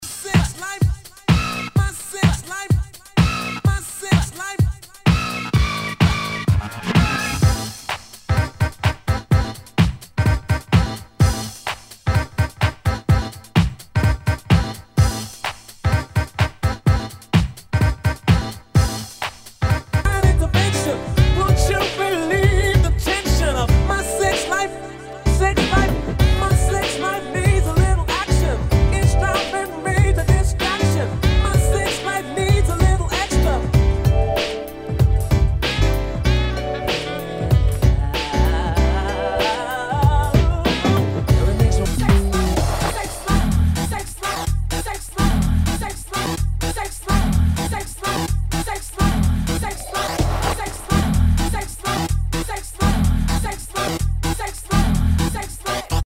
HOUSE/TECHNO/ELECTRO
ナイス！ヴォーカル・ハウス / ダウンテンポ！